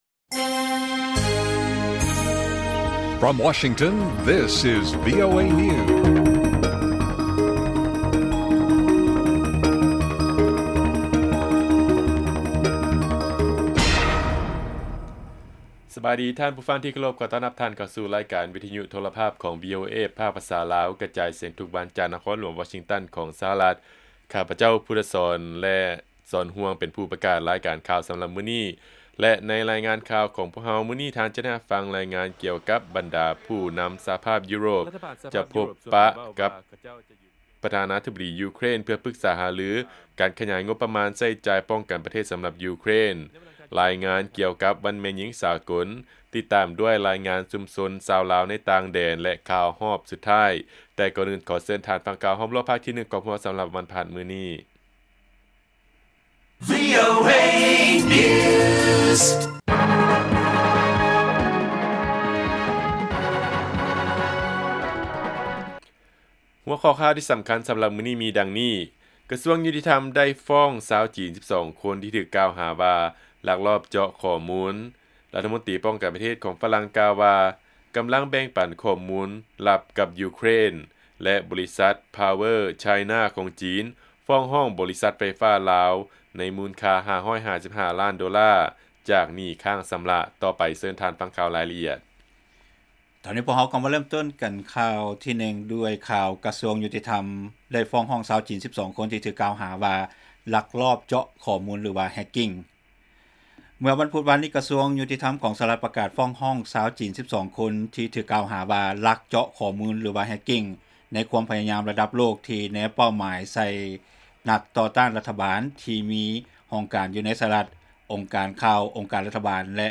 ລາຍການກະຈາຍສຽງຂອງວີໂອເອ ລາວ : ກະຊວງຍຸຕິທຳໄດ້ຟ້ອງຊາວຈີນ 12 ຄົນທີ່ຖືກກ່າວຫາວ່າ ລັກລອບເຈາະຂໍ້ມູນ ຫຼື hacking